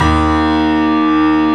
Index of /90_sSampleCDs/E-MU Producer Series Vol. 5 – 3-D Audio Collection/3DSprints/3DYamahaPianoHyb